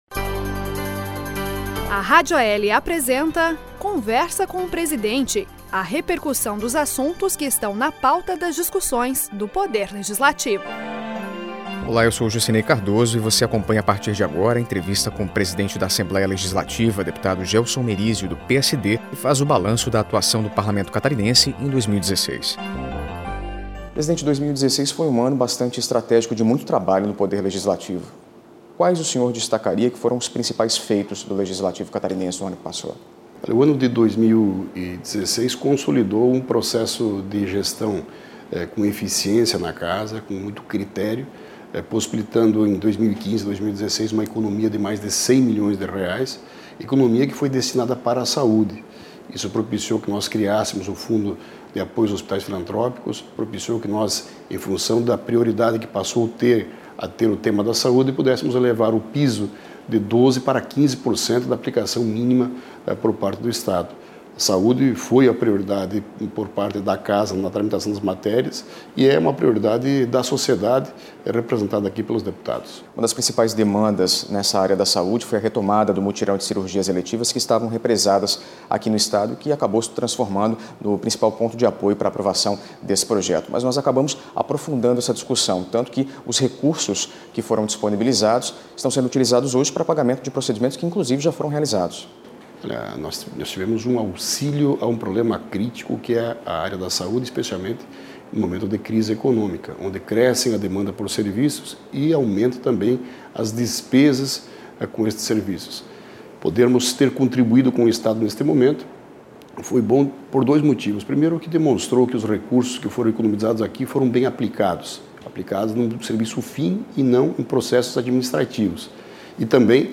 Conversa_com_o_presidente.mp3